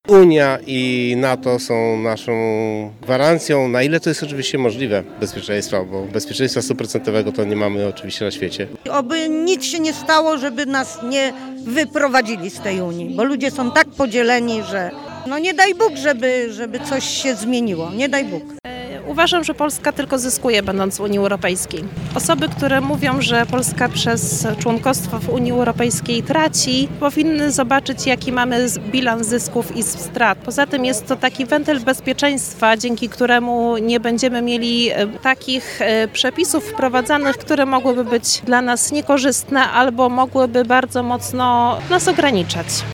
SZCZ-Sonda-UE.mp3